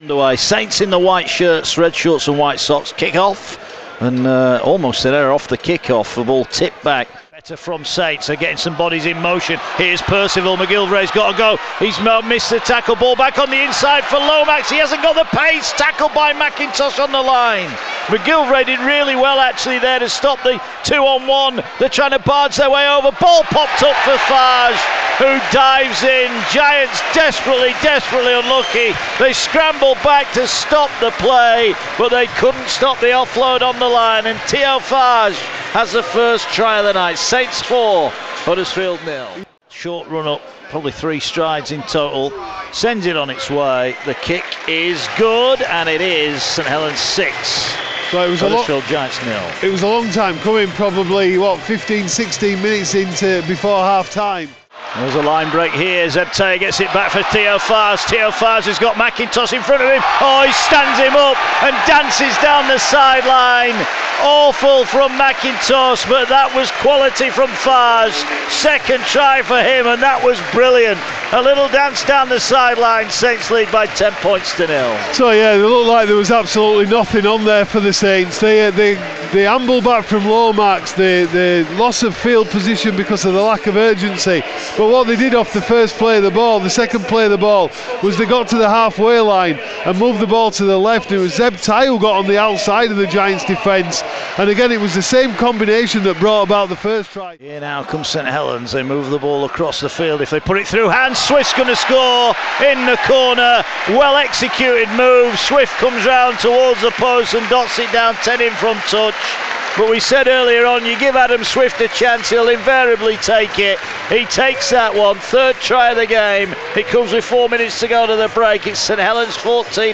Full match highlights as the Huddersfield Giants came back to draw 14-14 with St. Helens.